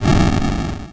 ihob/Assets/Extensions/explosionsoundslite/sounds/bakuhatu02.wav at master
bakuhatu02.wav